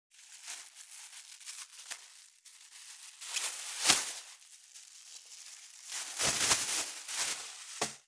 631コンビニ袋,ゴミ袋,スーパーの袋,袋,買い出しの音,ゴミ出しの音,袋を運ぶ音,
効果音